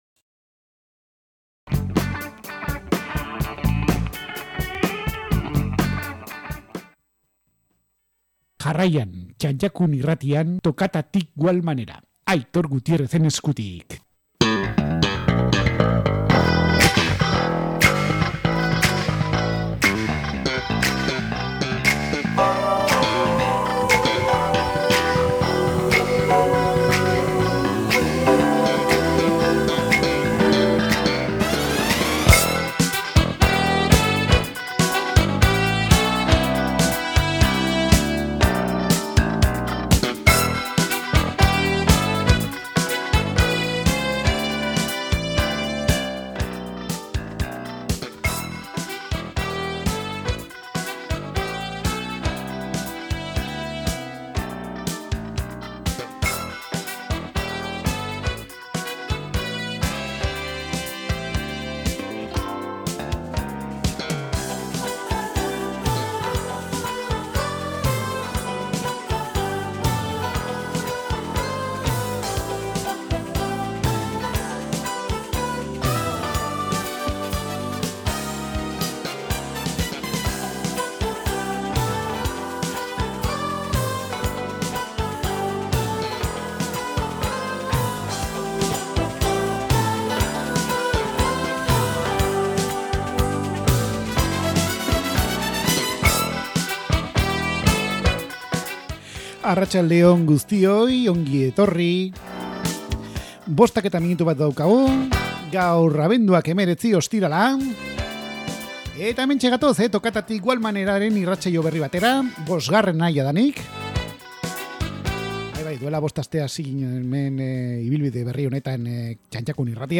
Italo disko izeneko dantza pistetan entuzten zen generoa